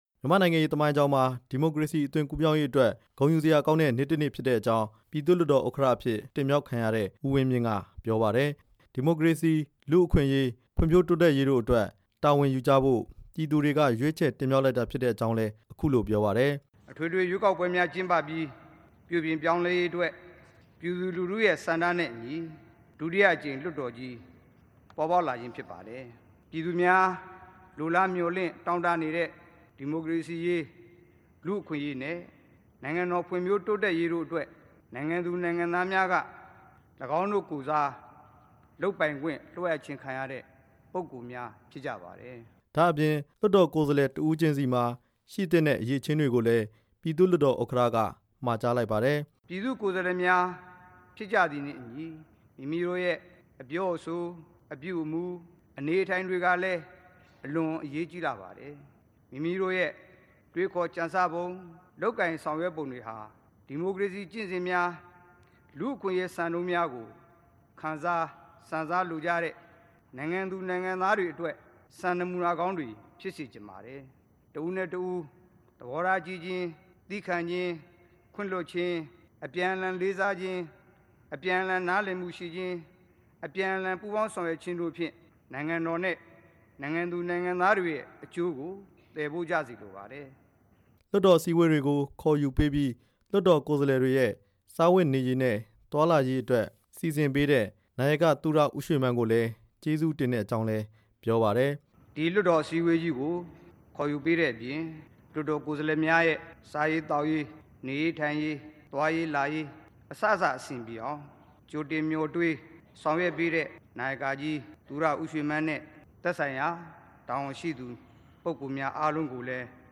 ပြည်သူ့လွှတ်တော်ဥက္ကဋ္ဌသစ် ဦးဝင်းမြင့်ရဲ့မိန့်ခွန်း